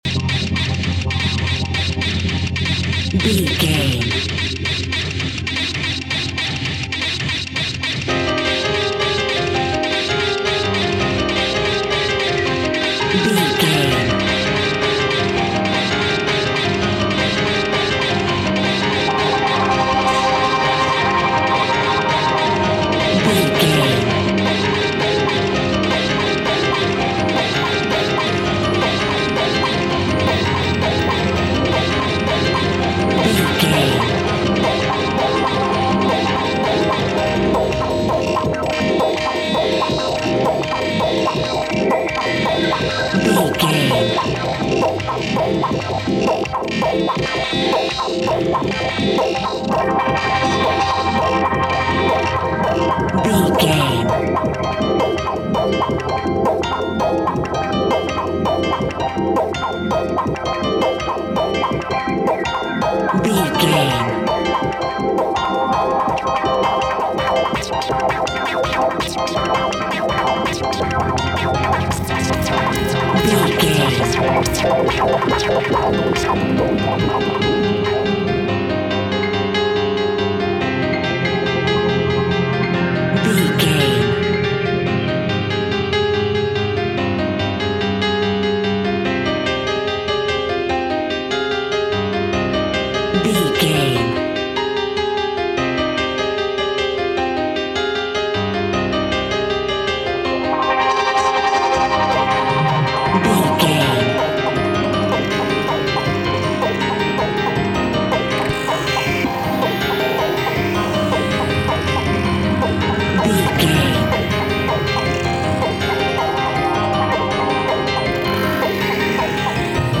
Classical Break Beat.
Aeolian/Minor
frantic
driving
energetic
hypnotic
dark
drum machine
synthesiser
piano
Drum and bass
electronic
sub bass